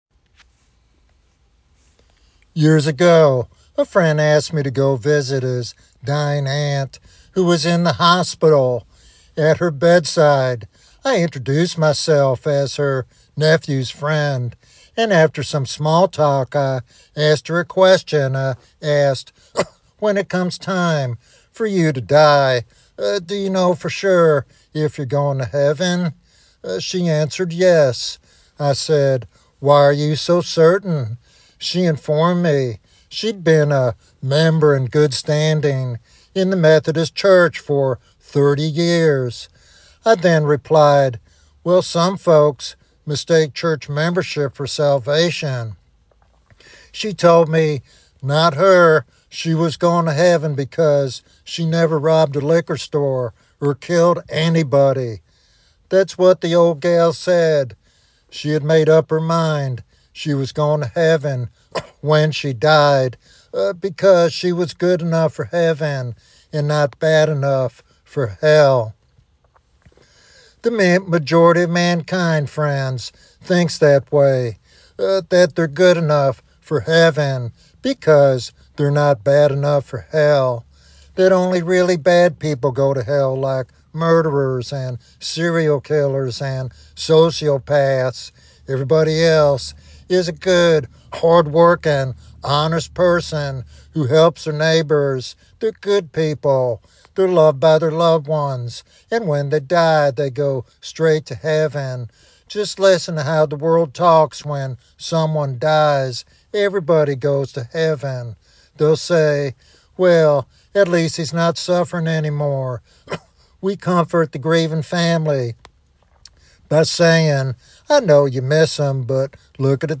In this compelling evangelistic sermon